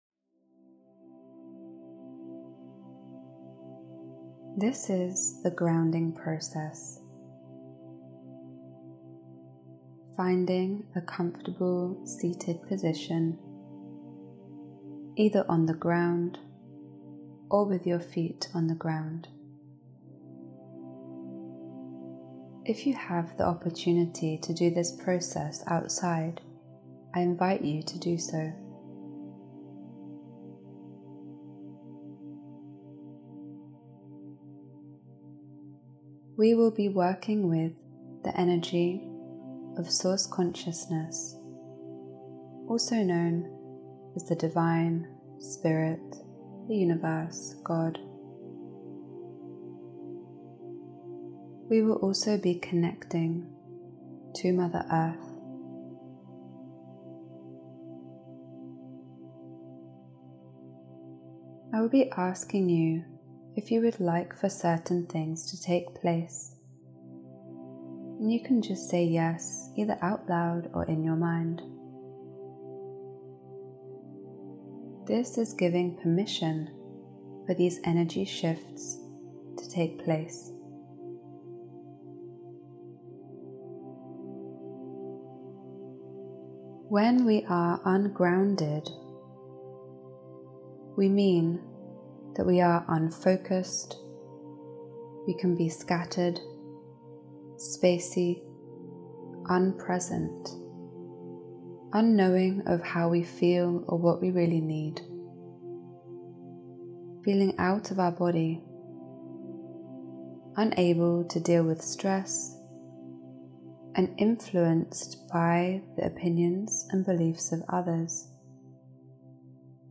As you listen you will receive healing energy throughout the guided session to most effectively ground you.
They use advanced Audio Entrainment techniques to allow Healing in the subconscious mind.